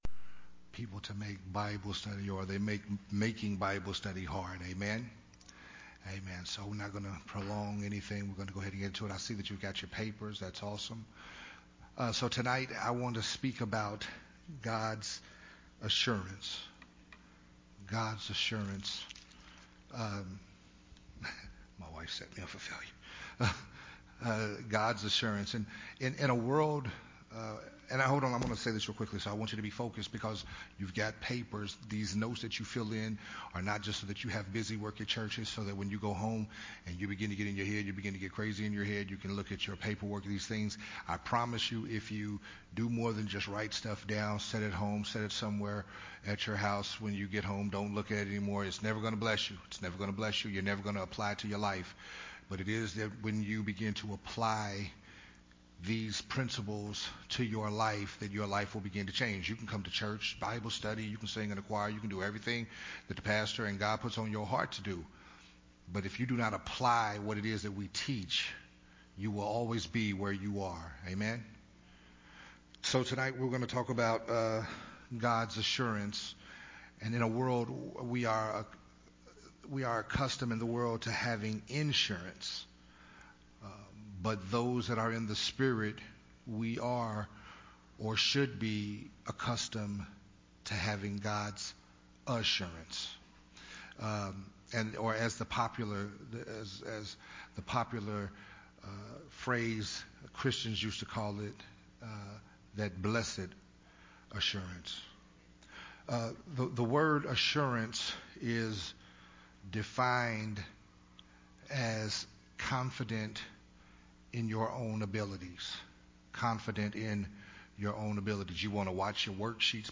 “God’s Assurances” is Part 1 of the Wednesday Evening Family Training Hour teaching series “Walking in the Word”.